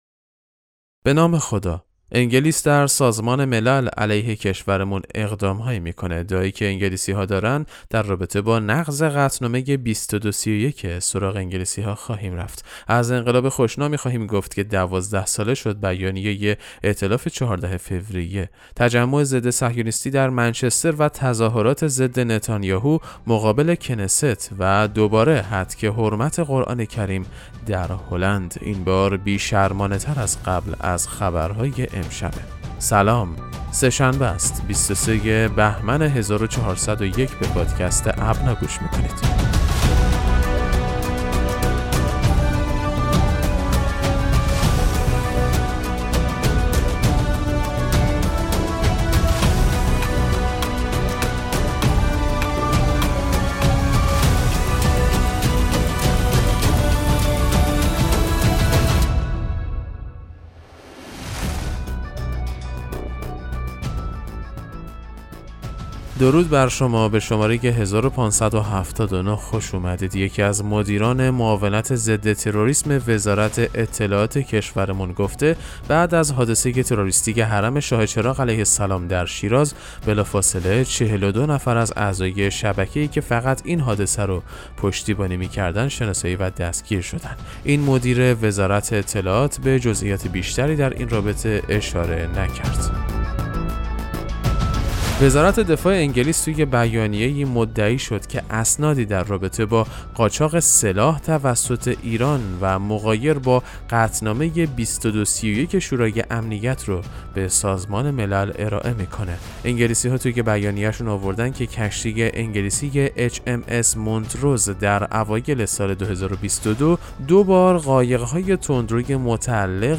پادکست مهم‌ترین اخبار ابنا فارسی ــ 25 بهمن 1401